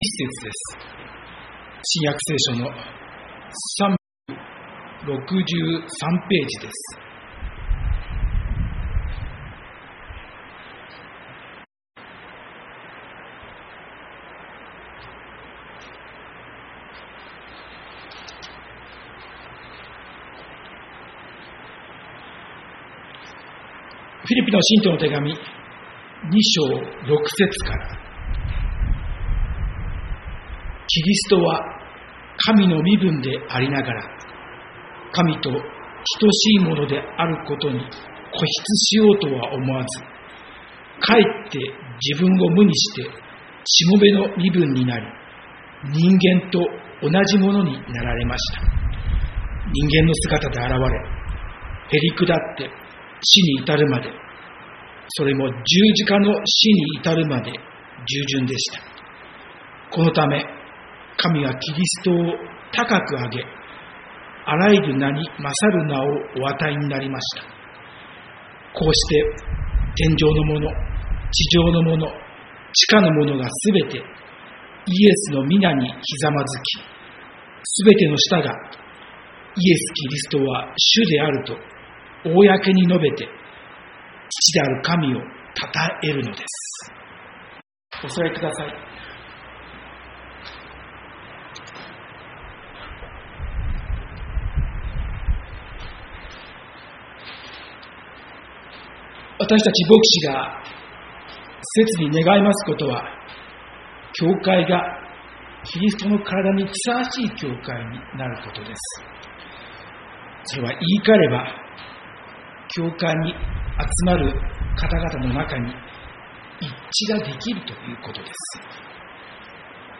キリストに倣いて 宇都宮教会 礼拝説教